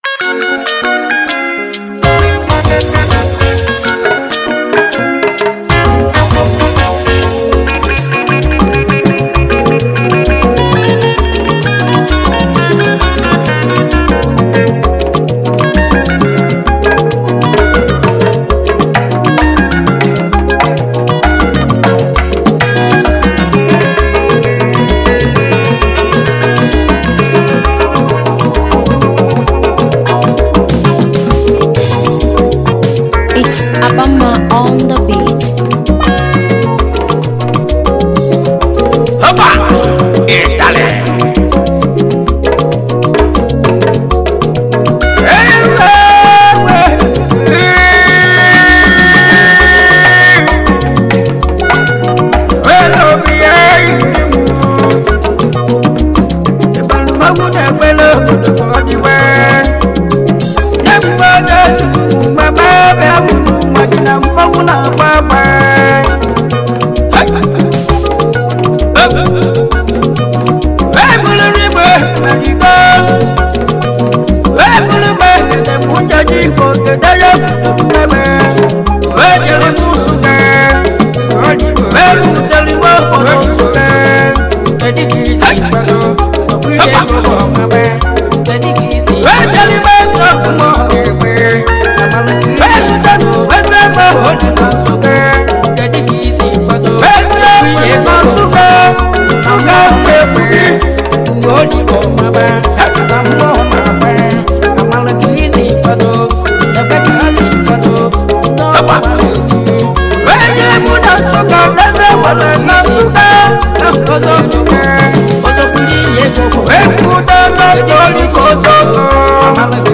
Bongo Highlife Ogene